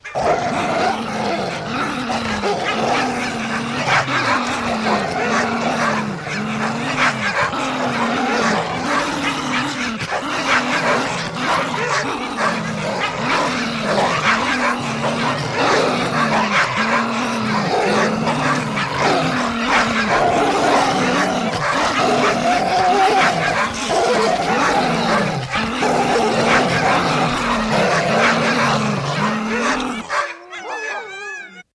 Лай разъяренной стаи псов в заточении, рвущихся в атаку